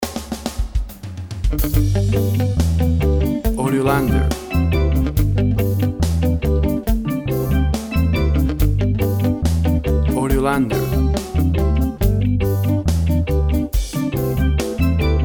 WAV Sample Rate 16-Bit Stereo, 44.1 kHz
Tempo (BPM) 70